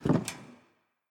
wheel_start_01.mp3